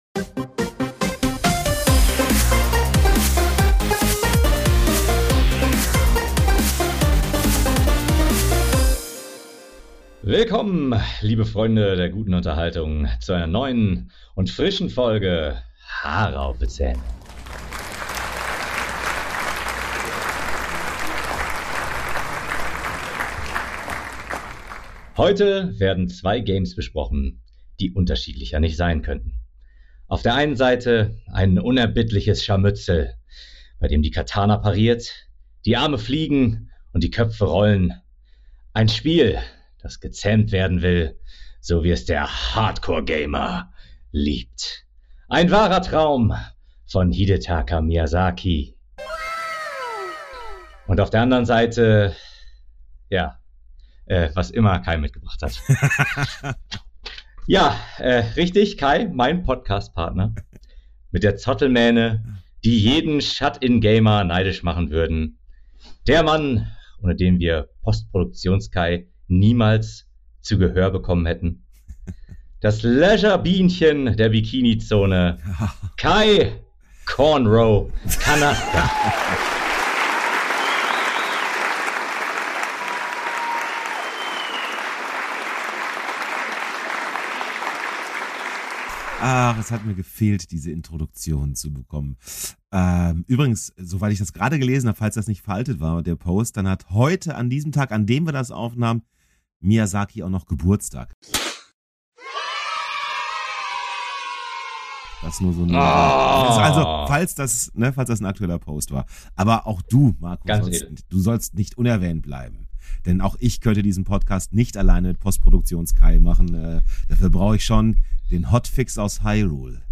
Mit den HAZ-Millionen Diktatorlike ein Mikro aus purem Gold gekauft.